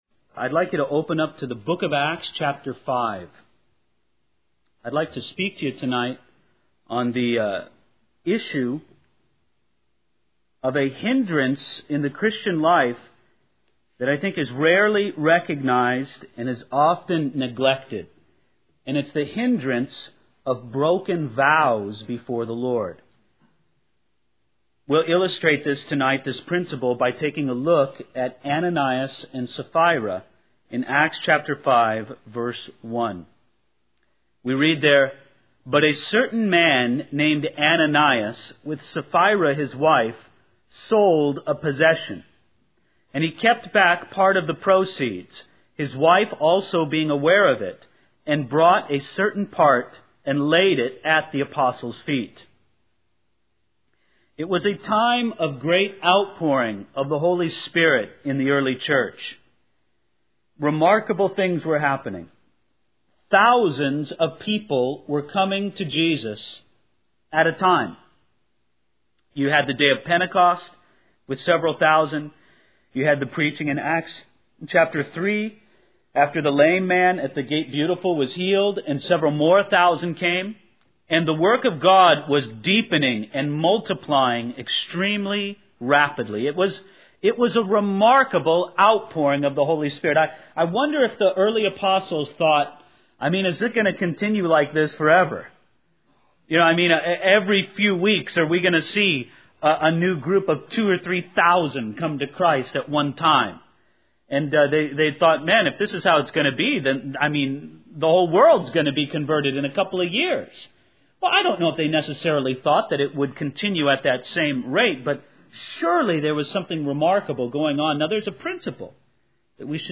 In this sermon, the preacher focuses on the importance of not making foolish vows before God. He emphasizes that it is better to not make vows at all than to make ones that we cannot fulfill.